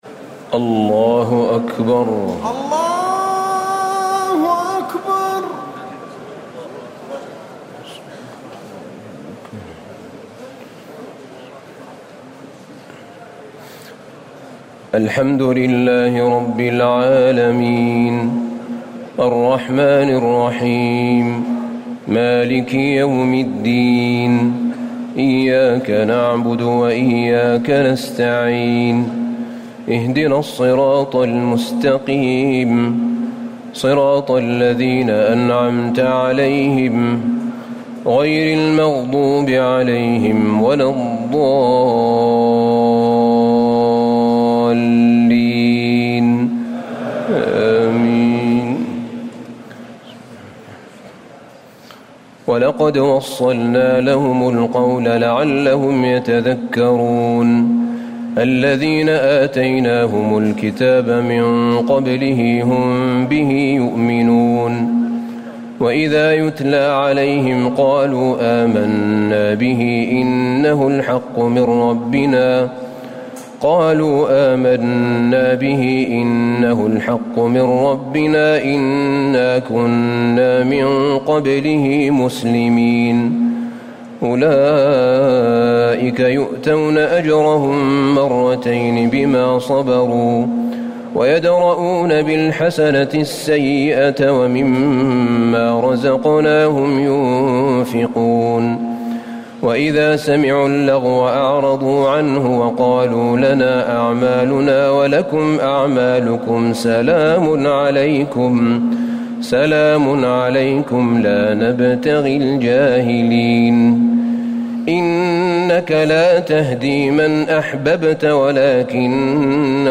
تراويح الليلة التاسعة عشر رمضان 1439هـ من سورتي القصص (51-88) والعنكبوت (1-45) Taraweeh 19 st night Ramadan 1439H from Surah Al-Qasas and Al-Ankaboot > تراويح الحرم النبوي عام 1439 🕌 > التراويح - تلاوات الحرمين